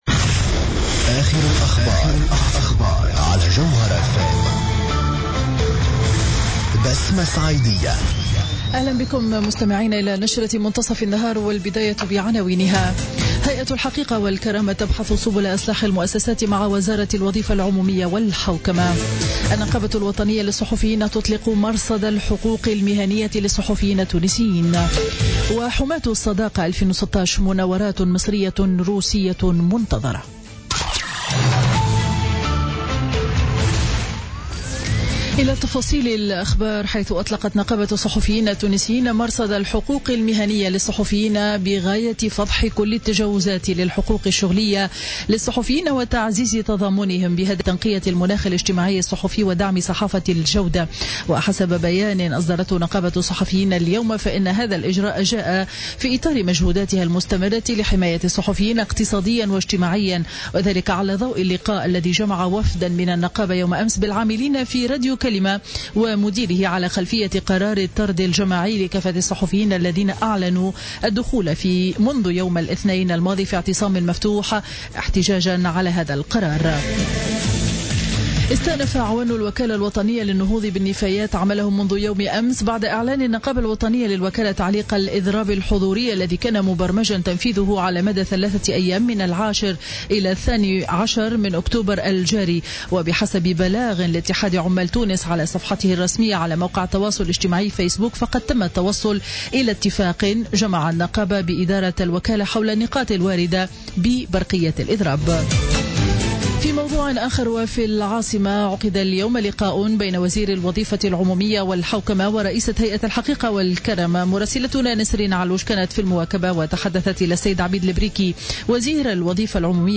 نشرة أخبار منتصف النهار ليوم الاربعاء 12 أكتوبر 2016